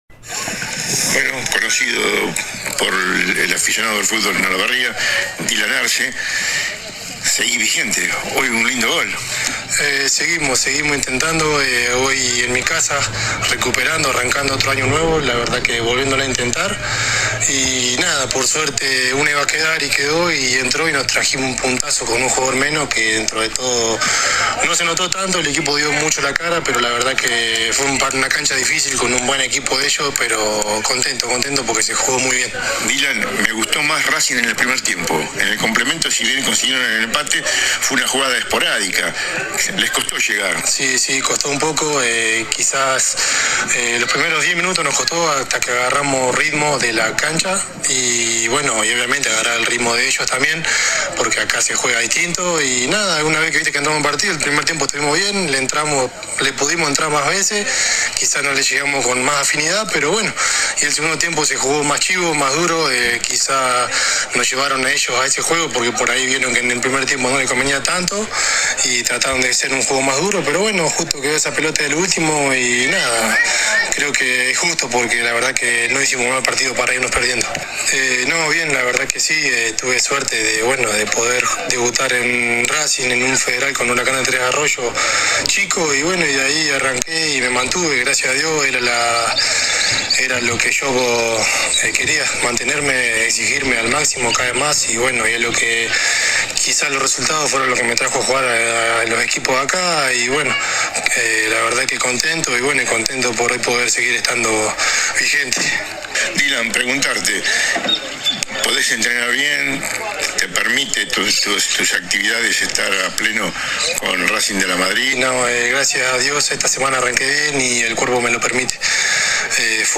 Terminado el partido dialogamos para «Emblema Deportivo».
Agregó además en la nota su felicidad por seguir vigente, ya que el año pasado estuvo complicado por una molesta pubalgia. AUDIO DE LA ENTREVISTA https